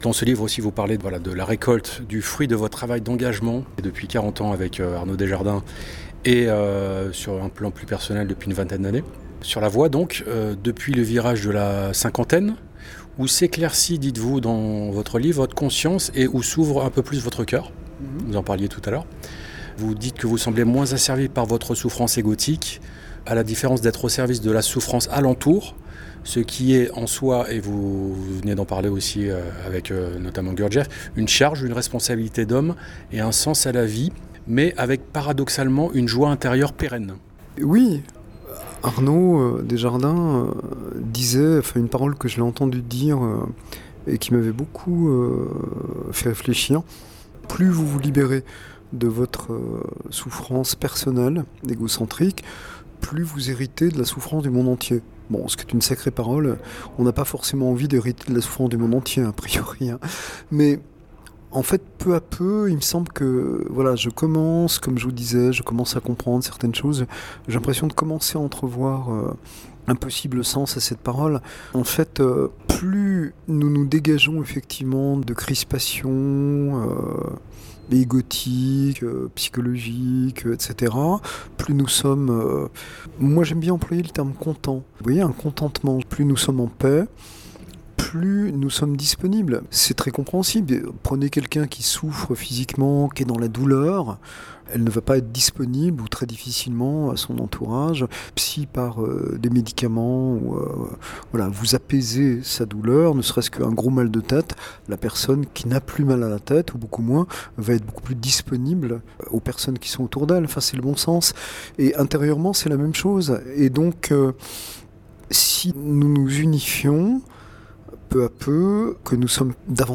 Entretien en trois parties (11, 8, 9 et 9 minutes) avec l'auteur :